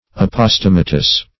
Search Result for " apostematous" : The Collaborative International Dictionary of English v.0.48: Apostematous \Ap`os*tem"a*tous\, a. Pertaining to, or partaking of the nature of, an aposteme.